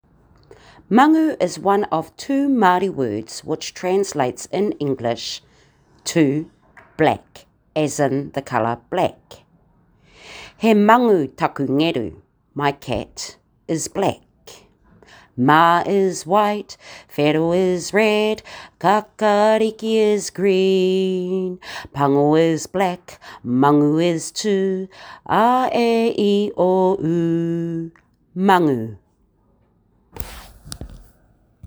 How to pronounce this correctly